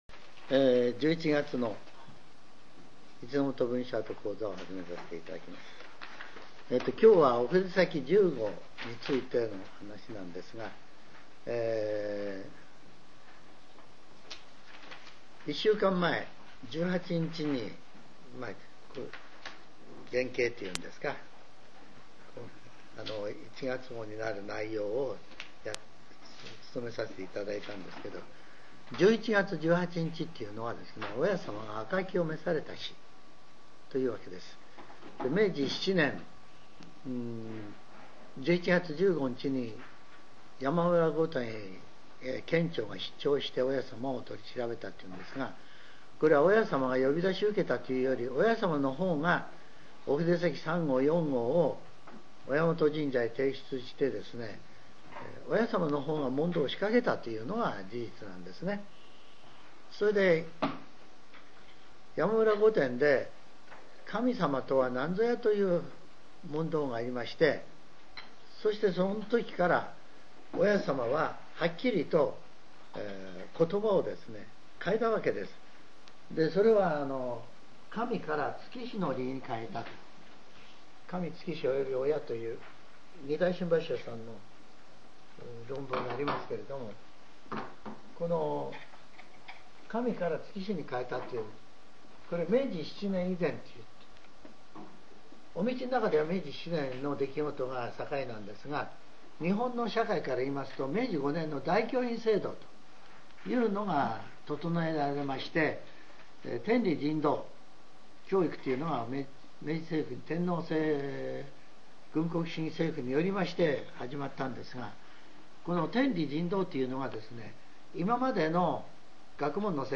全70曲中66曲目 ジャンル: Speech